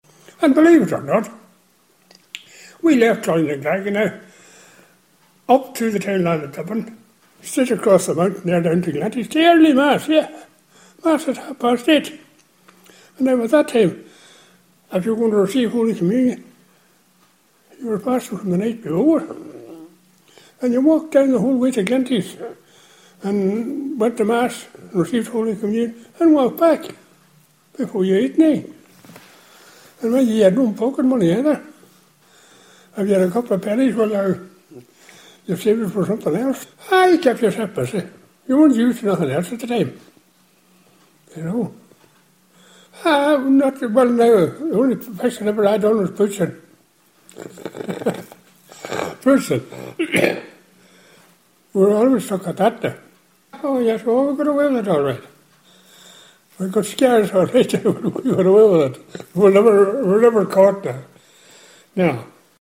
Our audio piece from local man